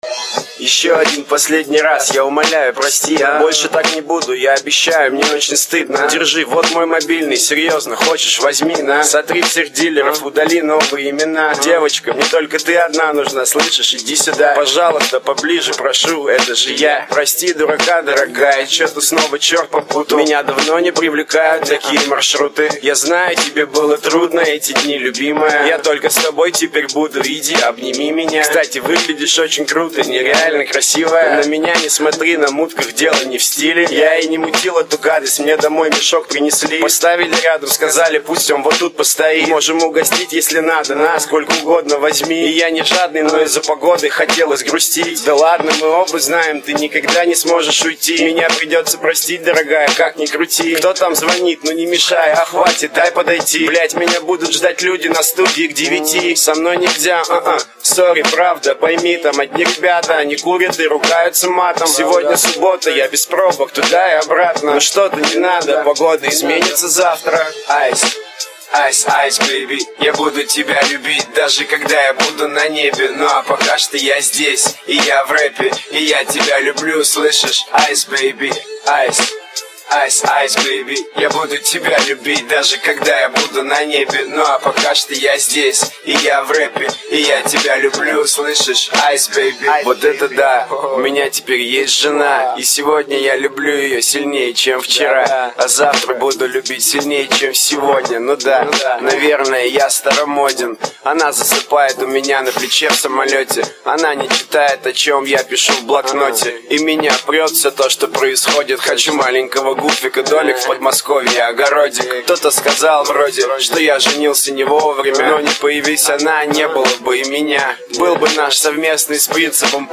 Назкочастотный фильтр накинули) Смешно)